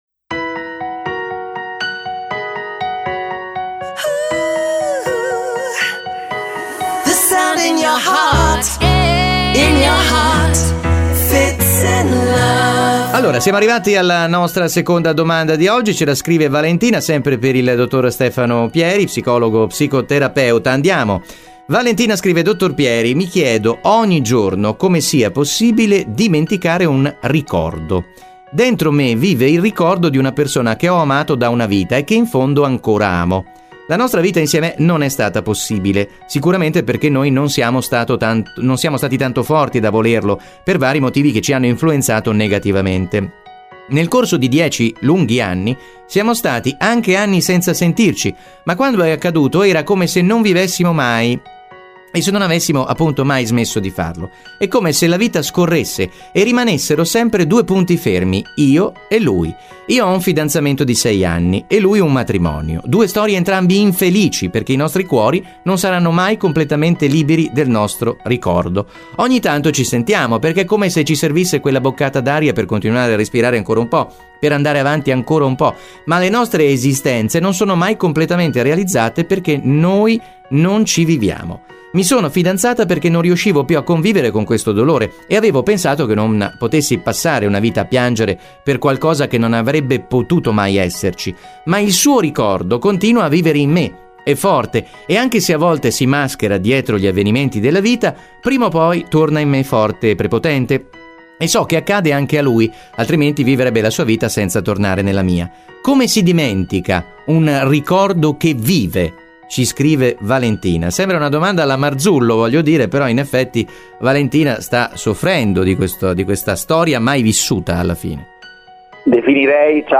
psicologo e psicoterapeuta